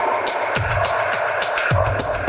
Index of /musicradar/dub-designer-samples/105bpm/Beats